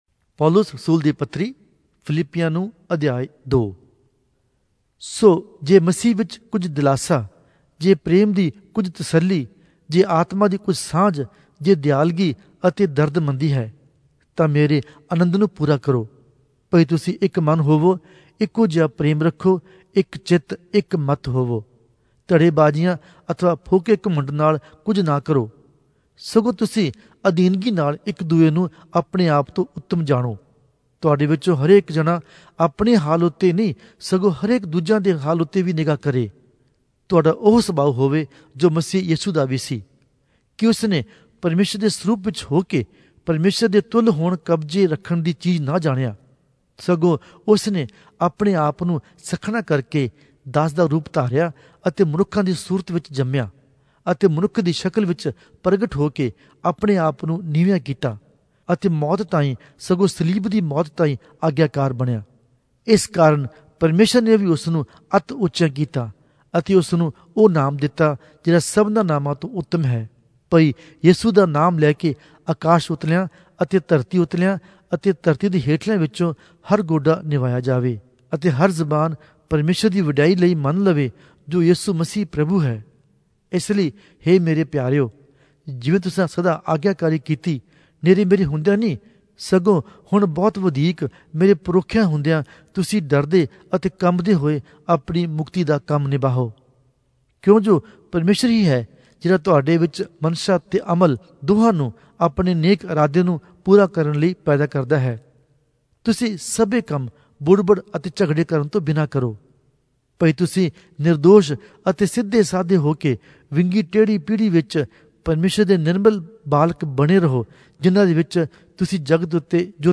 Punjabi Audio Bible - Philippians 3 in Esv bible version